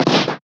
Cartoon Punch Cassette F
2D Animation Cassette Comic Fighting Game Punch SFX sound effect free sound royalty free Movies & TV